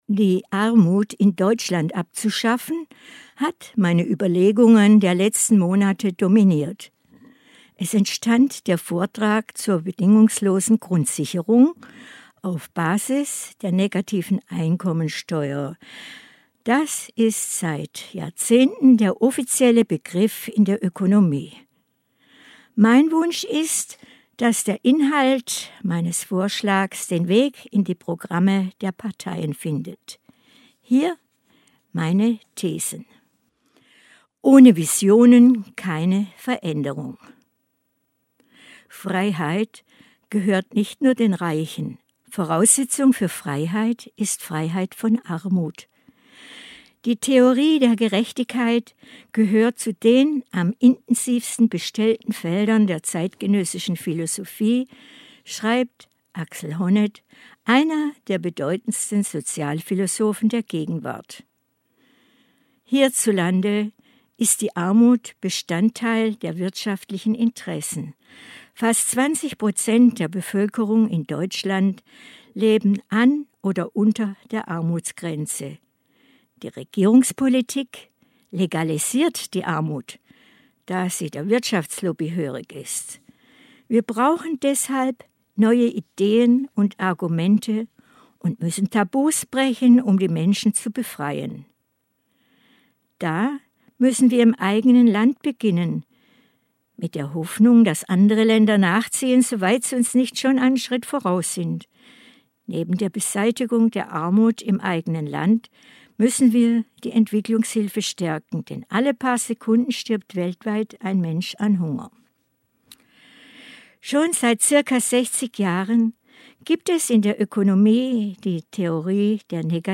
Vortrag zur Bedingungslosen Grundsicherung auf Basis der Negativen Einkommensteuer